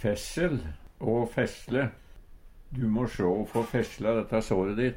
fessel/ å fessle - Numedalsmål (en-US)